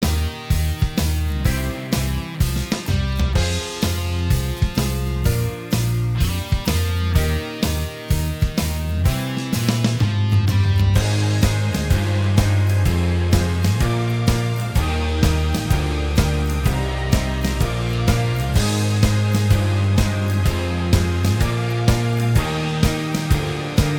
Minus Main Guitar Comedy/Novelty 3:44 Buy £1.50